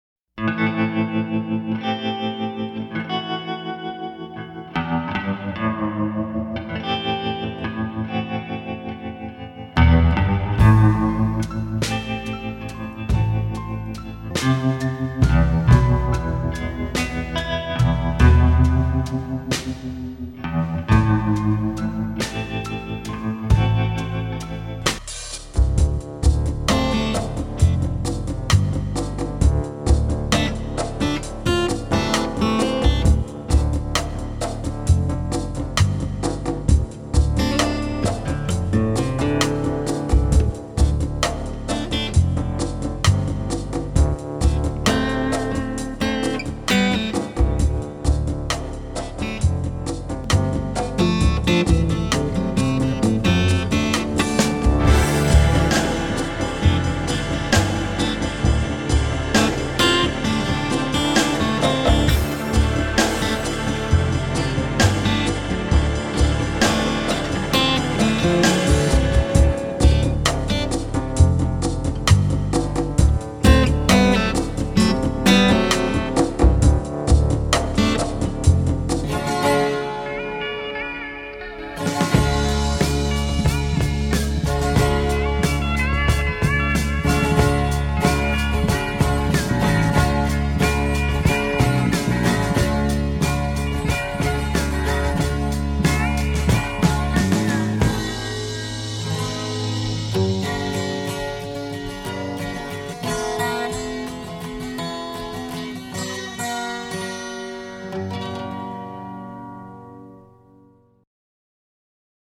Various roots rock-based themes .